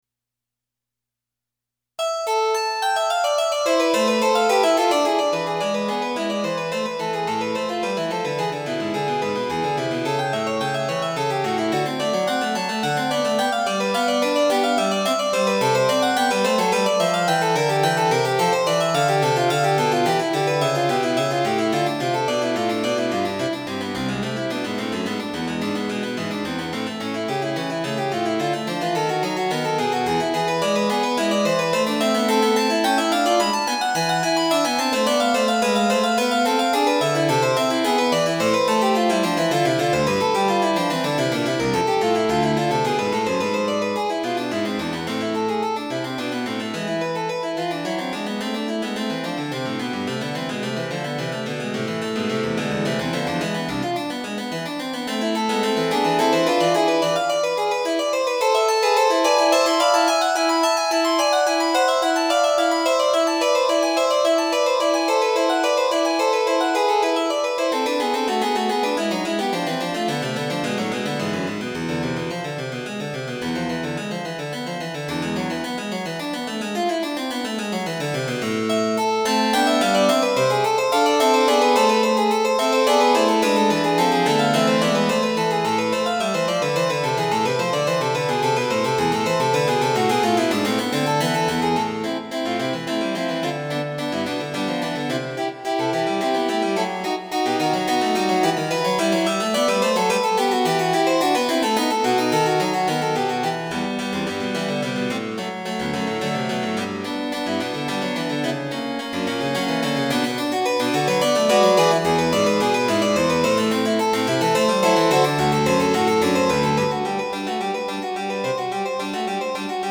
《 音の回想22 》電子音楽のバッハ
曲は同じJ.S.バッハのイギリス組曲で、こんどはかなり速いテンポの曲だったが、曲のテンポチエンジもあり、人が演奏する表現にかなりにまで近づいたものだった。
この曲を聞いてからもう10年近くが経ち、私も最近になってコンピューター音楽をやるようになったが、当時の貧弱な機具を使って、この長大なバッハのプレリュードを、よくここまで打ち込んだものだと感心した。